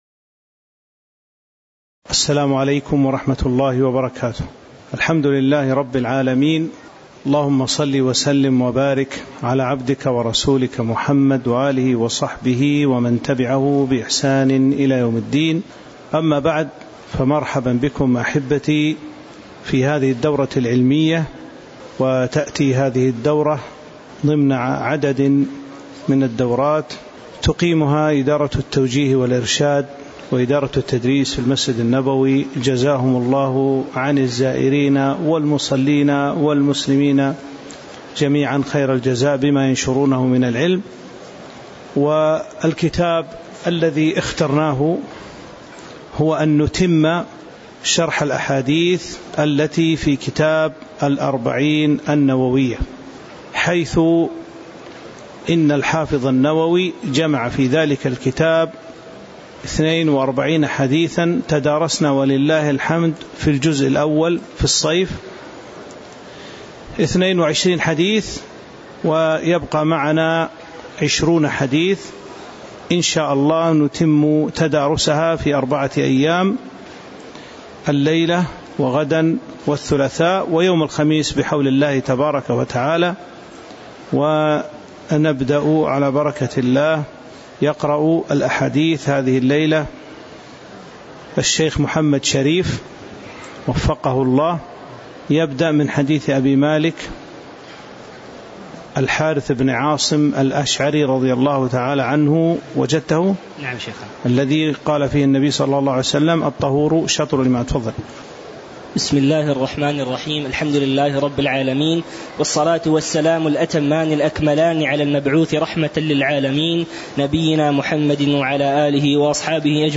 تاريخ النشر ١٨ جمادى الآخرة ١٤٤٥ هـ المكان: المسجد النبوي الشيخ